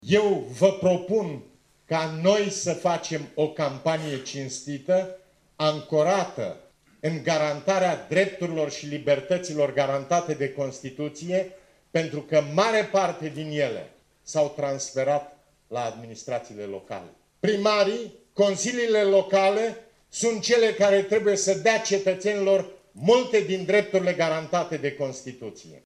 Iaşi: Traian Băsescu, prezent la evenimentul de lansare a candidaţilor PMP la alegerile locale